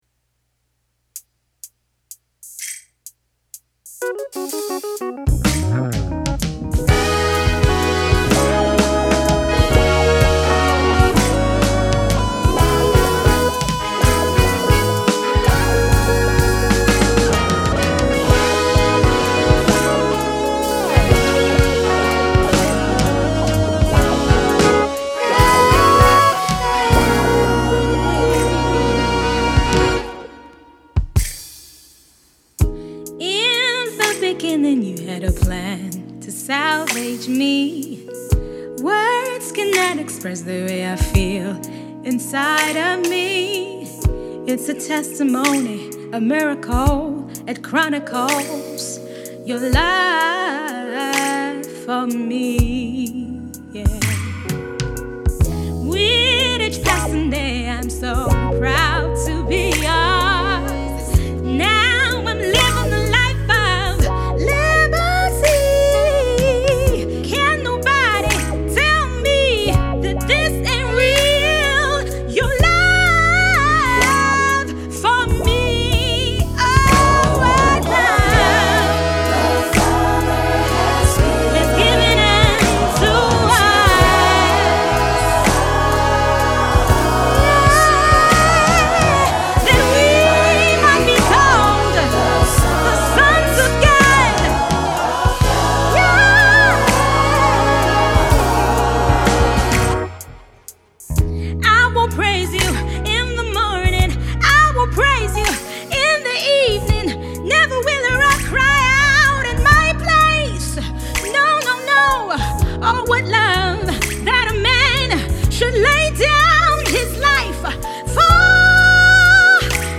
gospel
soulful